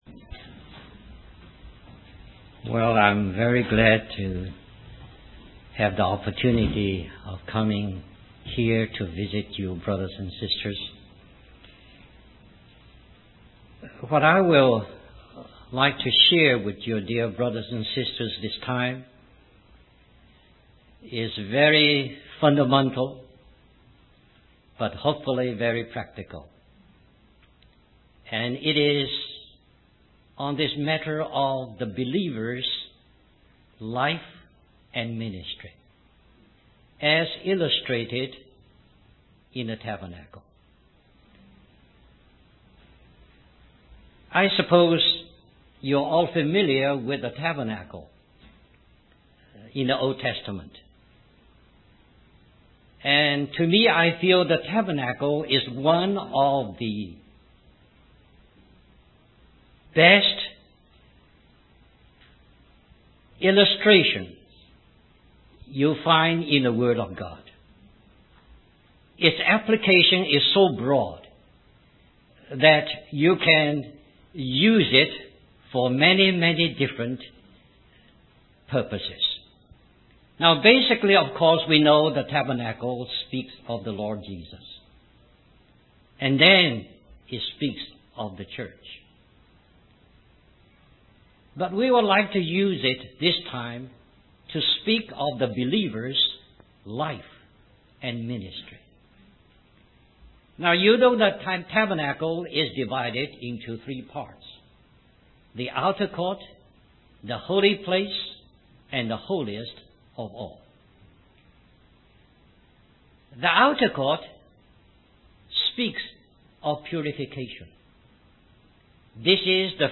In this sermon, the preacher focuses on the concept of purification and service in the believer's life. He explains that the water mentioned in 1 John 5 represents the life of Jesus within us, which cleanses us daily.